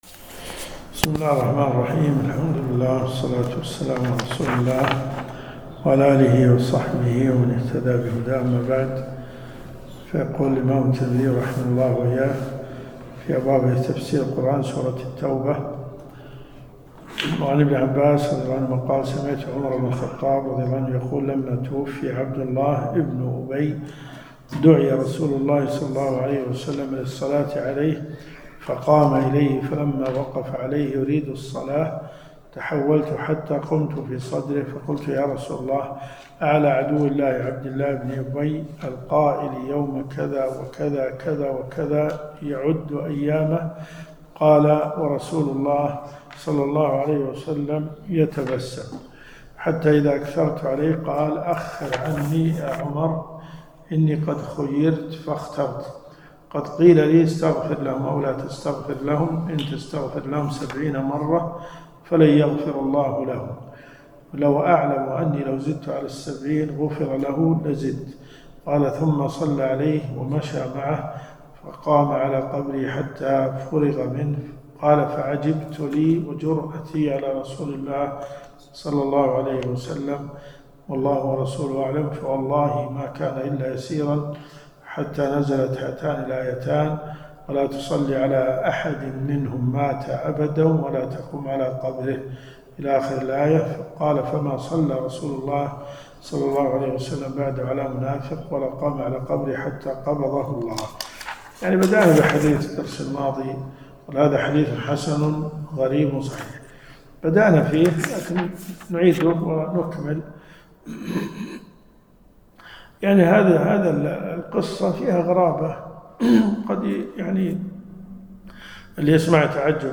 دروس صوتيه ومرئية تقام في جامع الحمدان بالرياض - فتاوى .